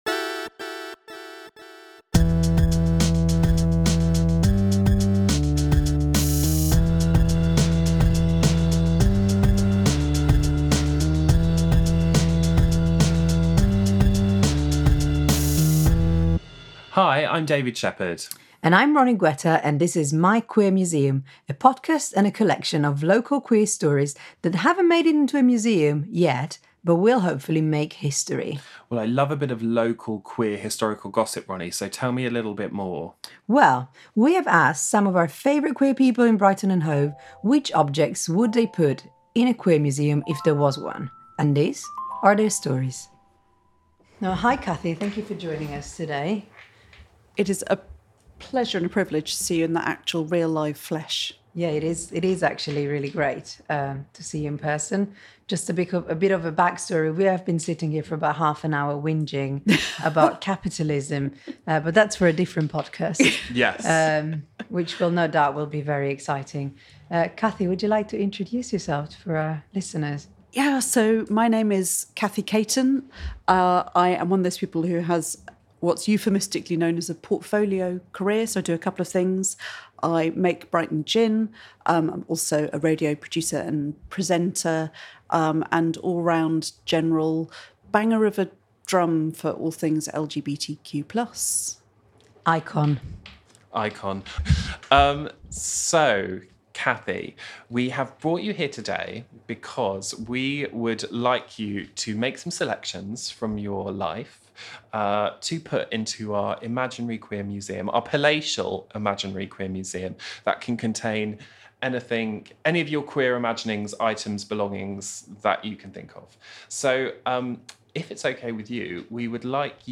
Recorded on October 19, 2020, at The Spire, Brighton, as part of the My Queer Museum podcast Interview by